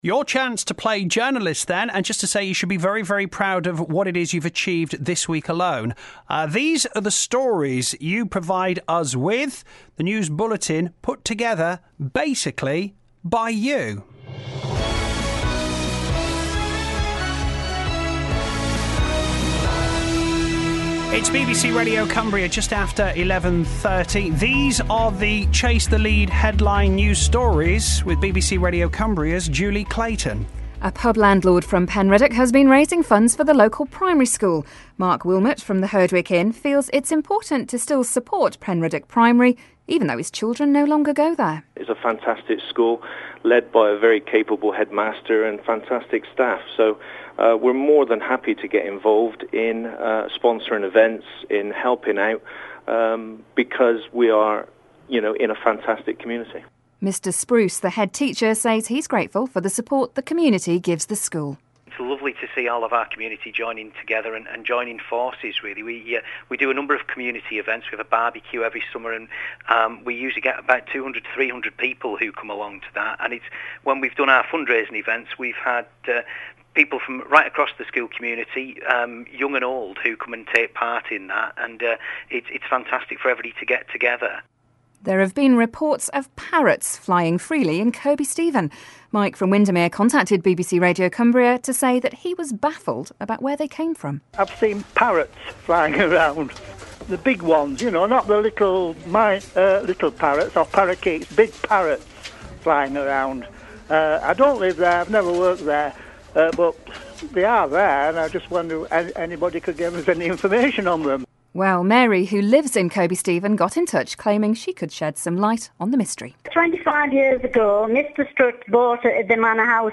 This is the news bulletin put together by BBC Radio Cumbria listeners this week.